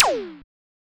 laser4.wav